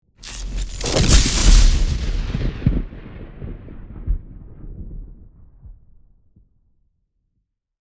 Electrifying Lightning Strike 3 Sound Effect Download | Gfx Sounds
Electrifying-lightning-strike-3.mp3